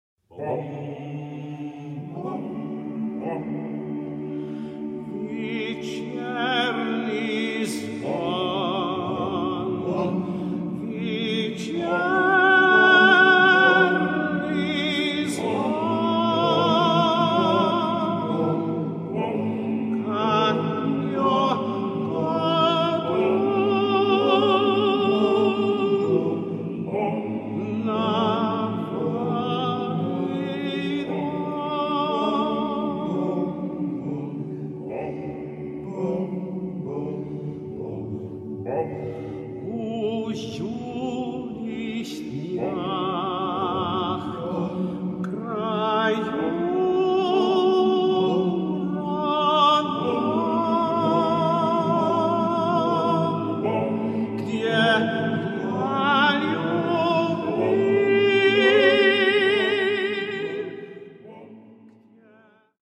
Solist